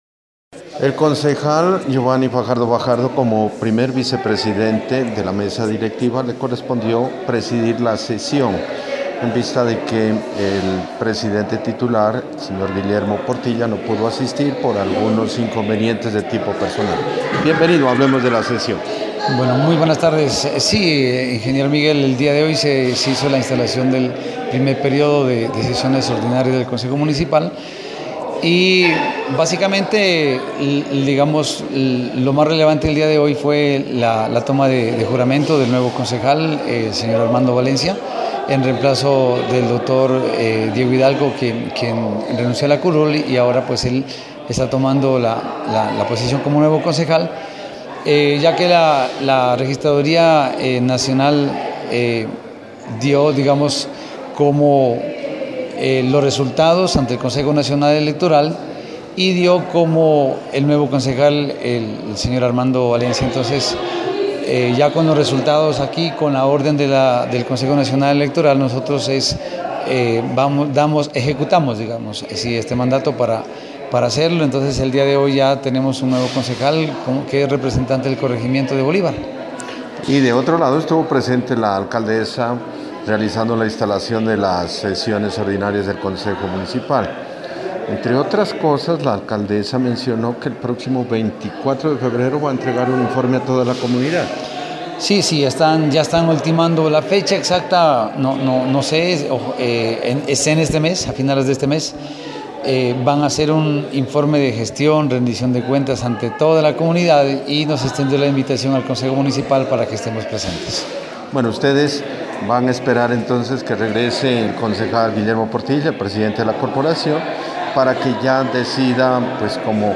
Al final de la sesión dialogamos con el primer vicepresidente Geovanny Fajardo Fajardo.